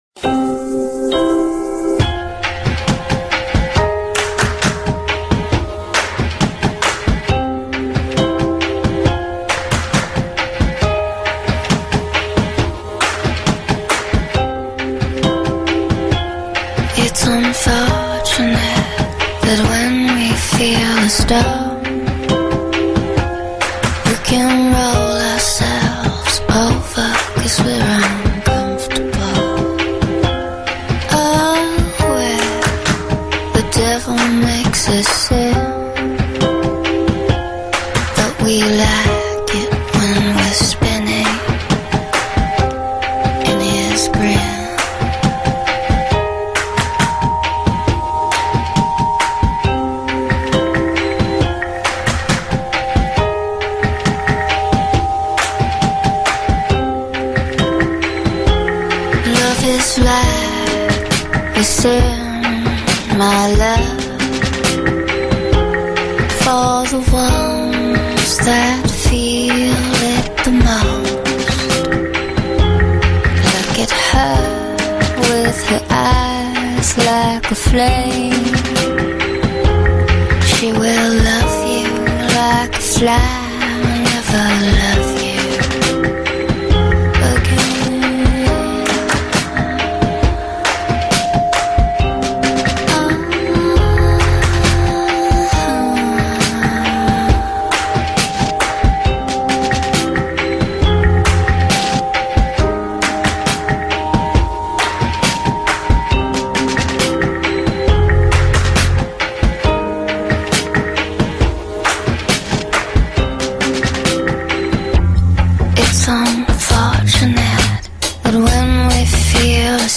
Tag: Trip Hop
Dark   and mesmerizing, but propelled by a hypnotic beat
sultry voice